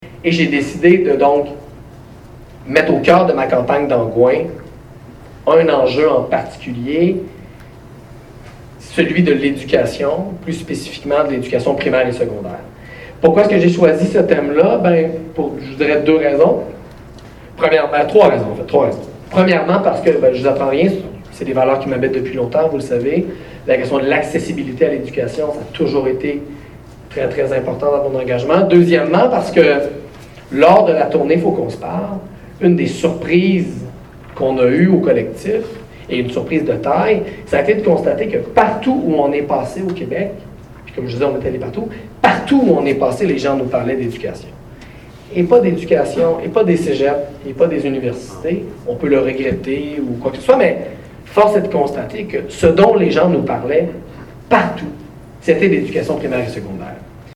en entrevue avec Gabriel Nadeau-Dubois.